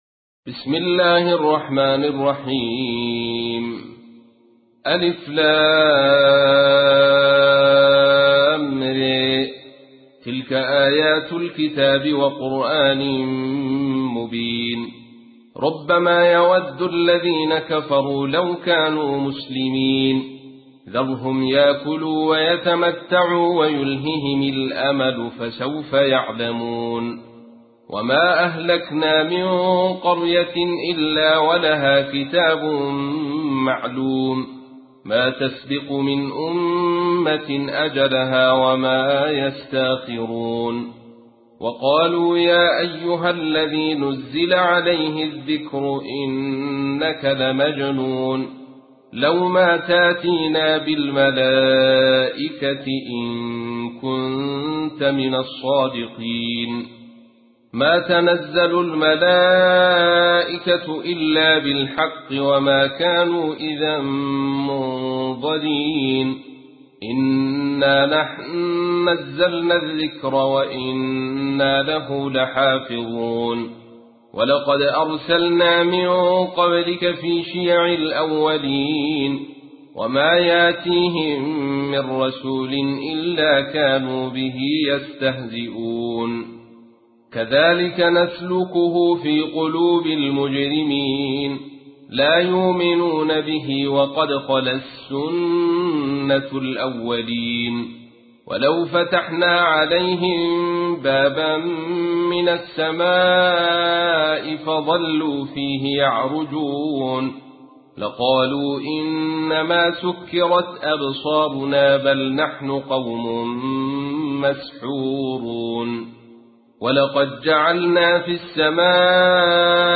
تحميل : 15. سورة الحجر / القارئ عبد الرشيد صوفي / القرآن الكريم / موقع يا حسين